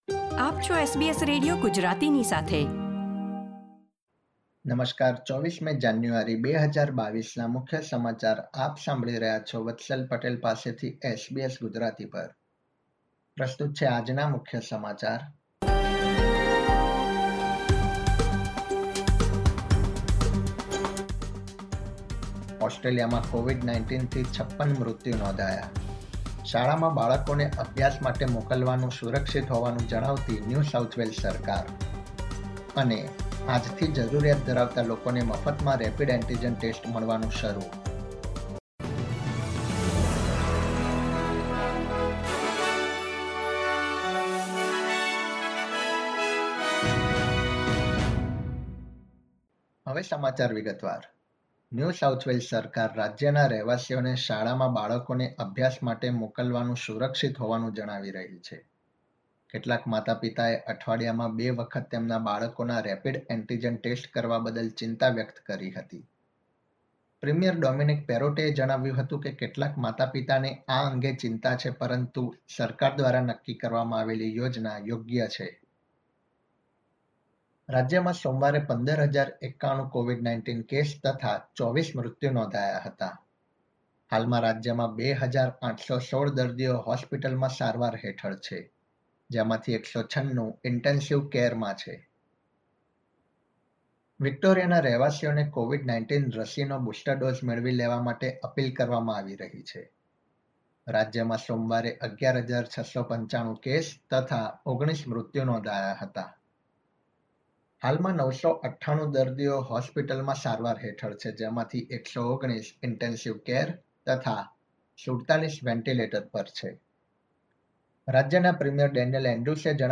SBS Gujarati News Bulletin 24 January 2022